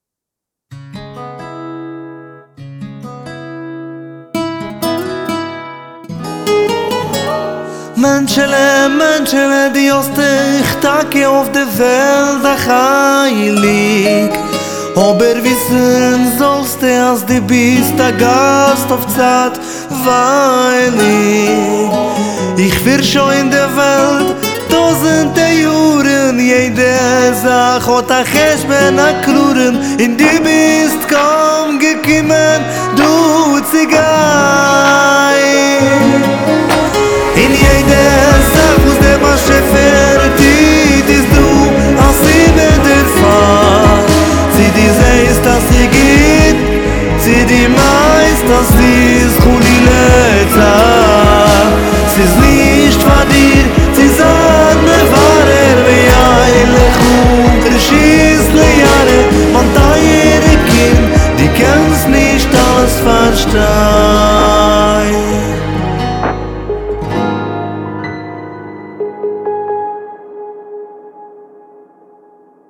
במיקס יש לי עוד מה להשתפר קאבר.mp3